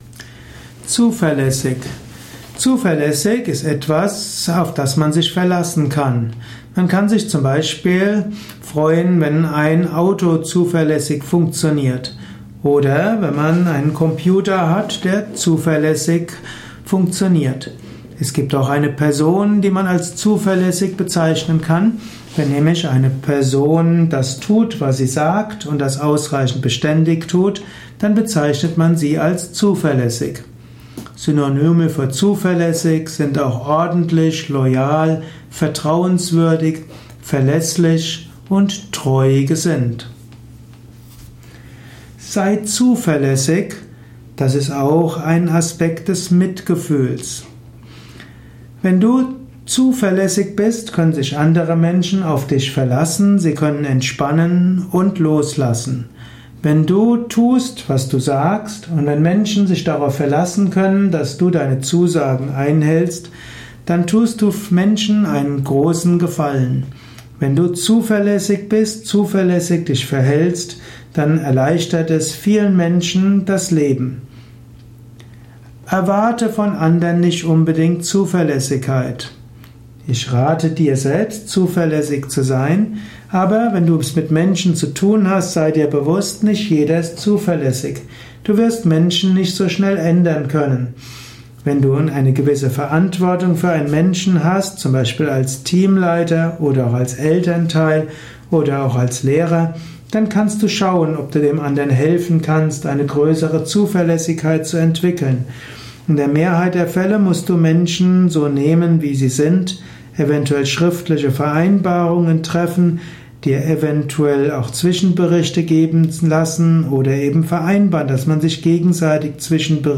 Dies ist nur ein Kurzvortrag - magst du das ergänzen?